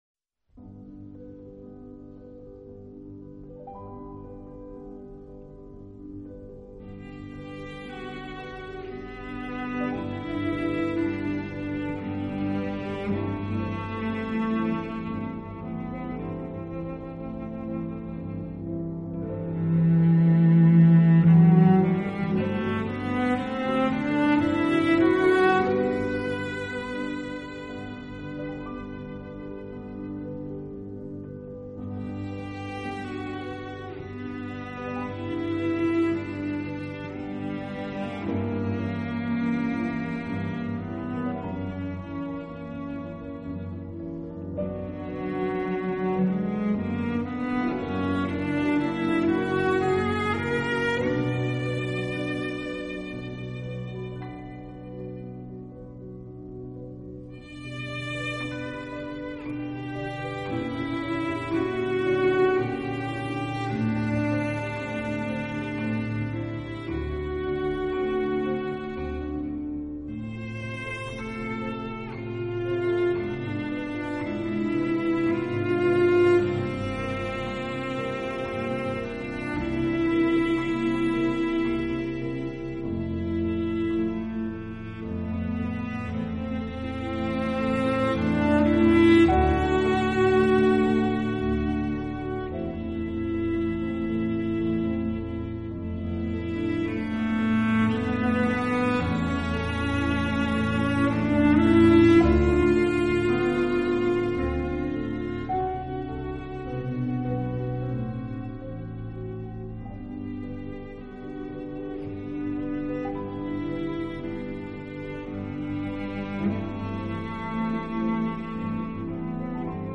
类型: 减压音乐
这些曲目都是旋律优美，雅俗共赏的经典。